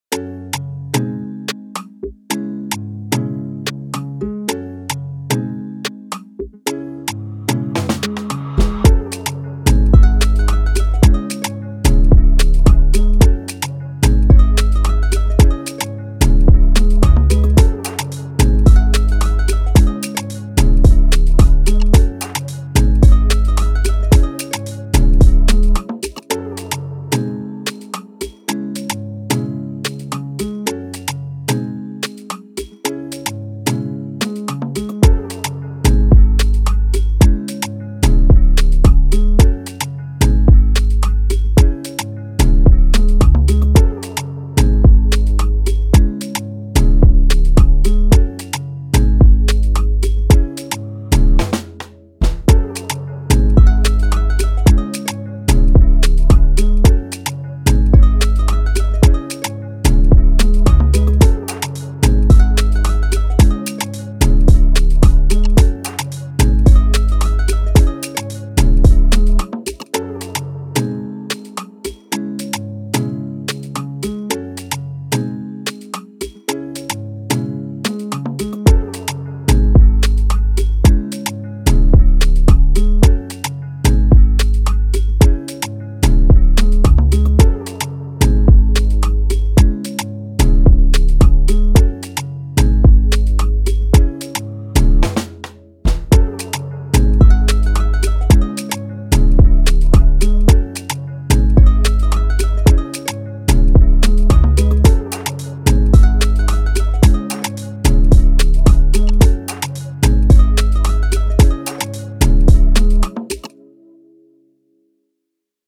Afrobeat, Dance
C# min